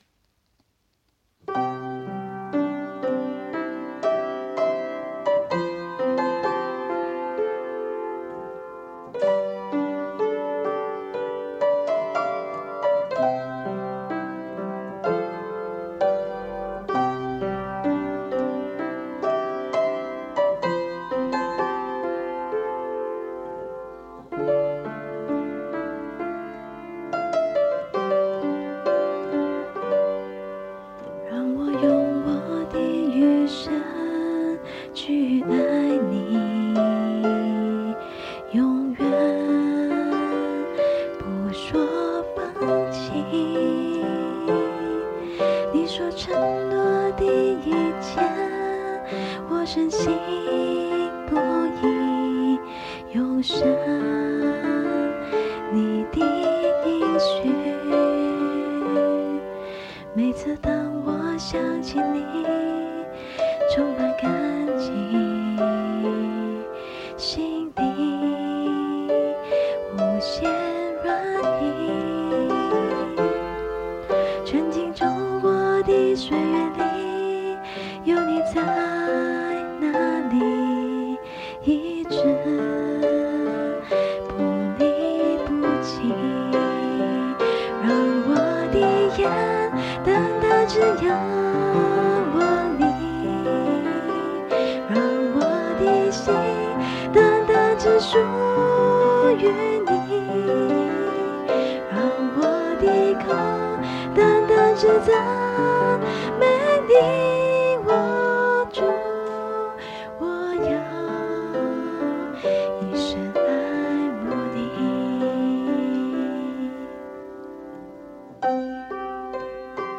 【原创圣歌】|《单单》
这首歌只简单的用了我家的钢琴和风琴里的笛子声来录制。因为没有什么录音器材，所以全部的录音都是在家里，用vivo手机录制。先录钢琴声，然后笛子声，主音，最后副歌的配音。